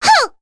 Rehartna-Vox_Attack3.wav